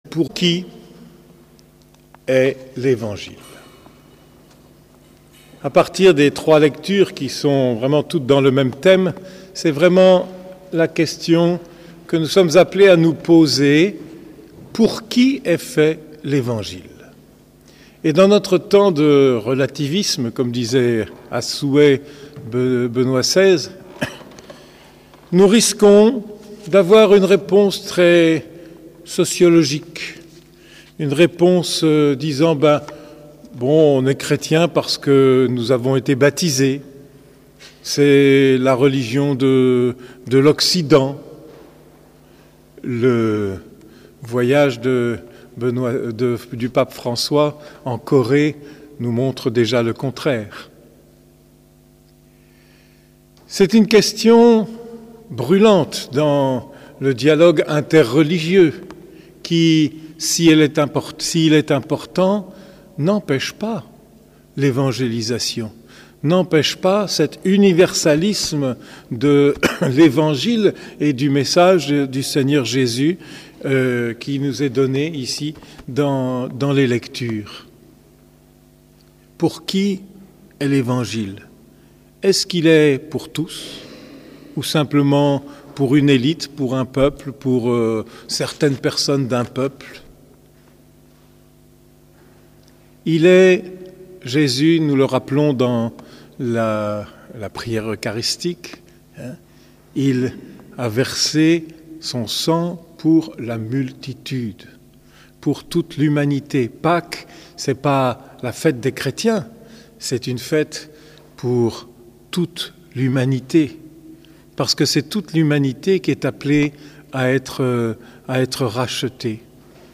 Homélie du 20e dimanche du Temps Ordinaire